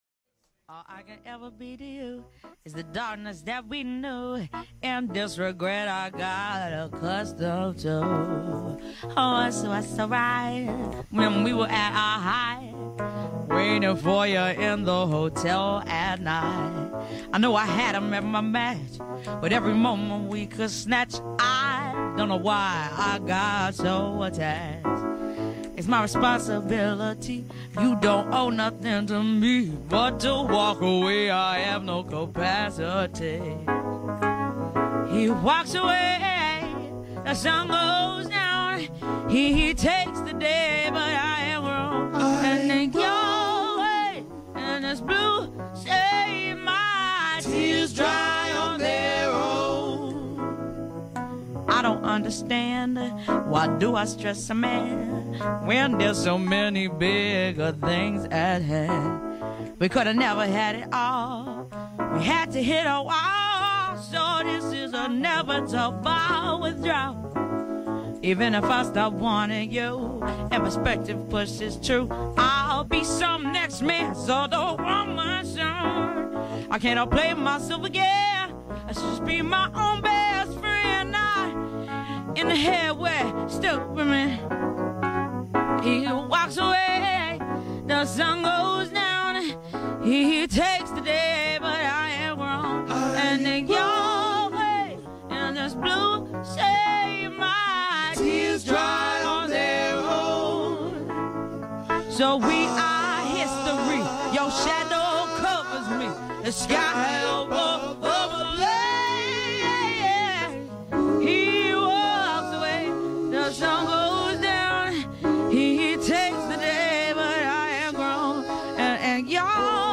singing in a fans bedroom with only a keyboard